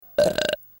• Качество: высокое
Отрыжка Джорджа